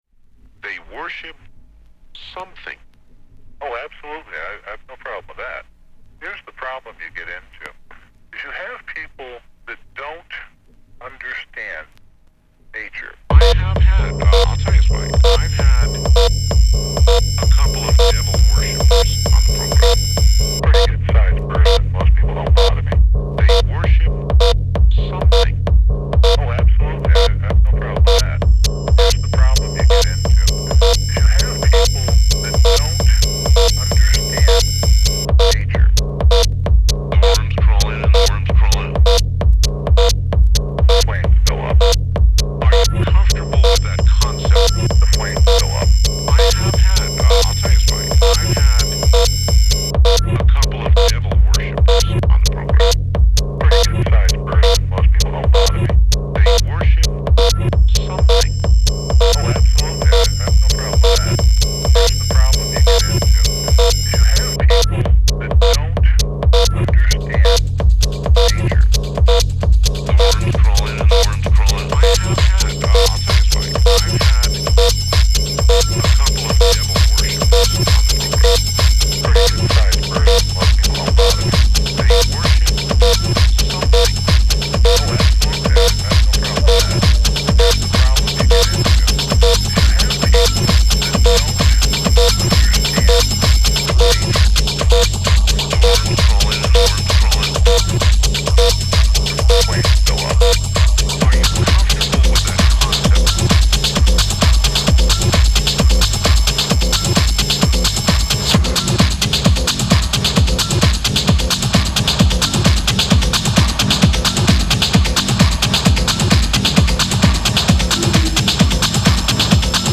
テクノ
今日、午前中に録ったアナログミックスです。
暗くて重い。